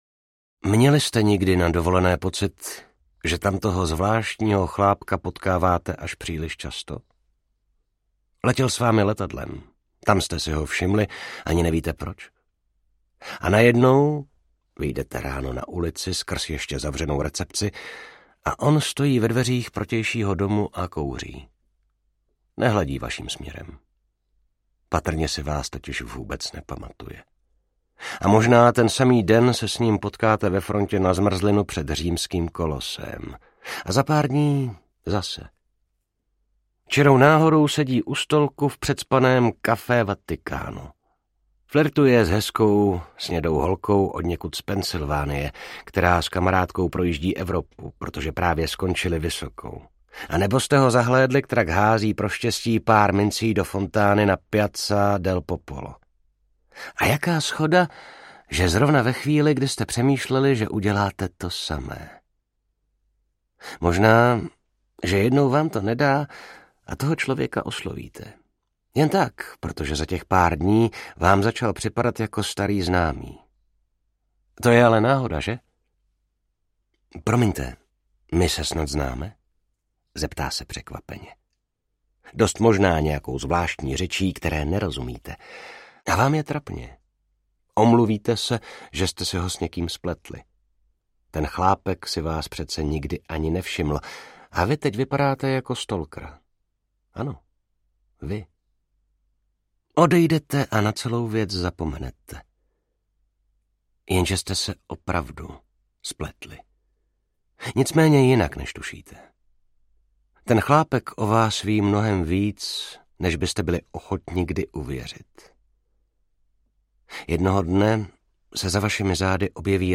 Rochus audiokniha
Ukázka z knihy
Vyrobilo studio Soundguru.